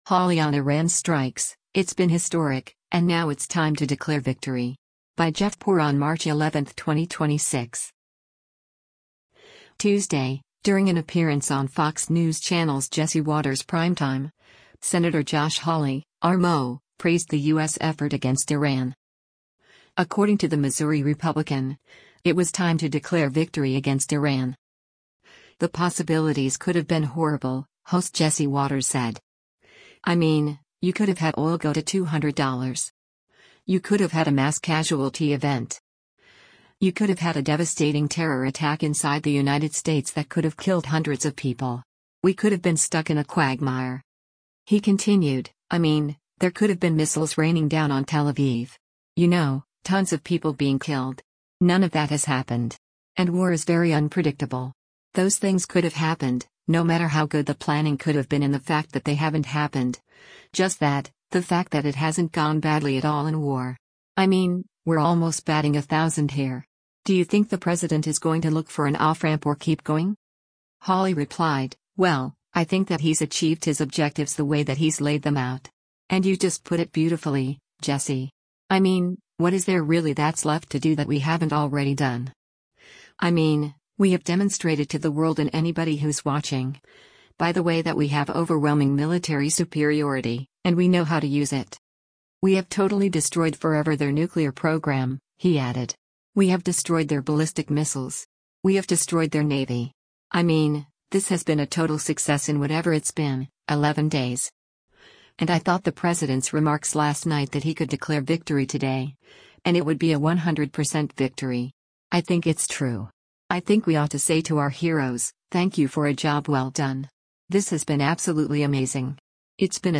Tuesday, during an appearance on Fox News Channel’s “Jesse Watters Primetime,” Sen. Josh Hawley (R-MO) praised the U.S. effort against Iran.